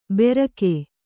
♪ berake